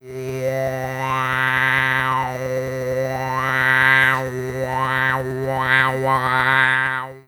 Sonido de digiridoo hecho con voz humana
modulación
Sonidos: Especiales
Sonidos: Voz humana